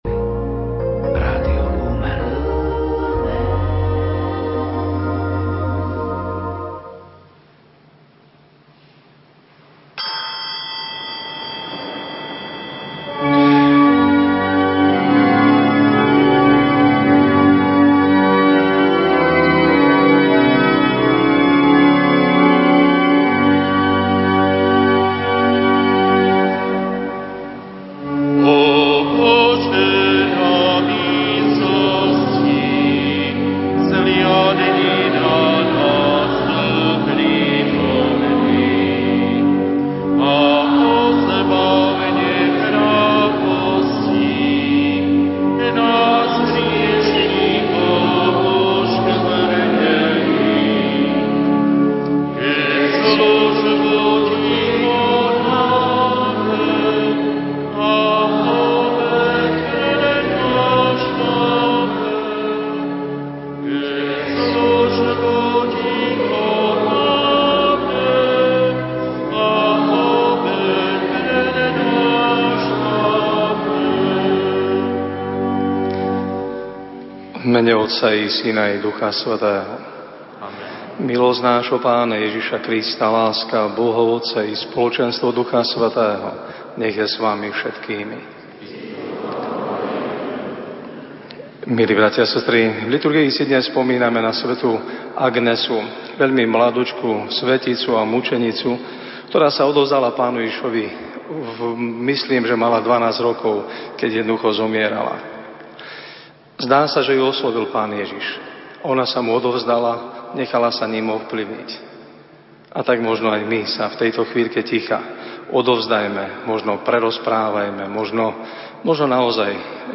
Svätá omša na rádiu Lumen | Obec Chmeľnica
Záznam svätej omše Vám ponúkame cez archív rádia Lumen, ale aj na našej obecnej stránke.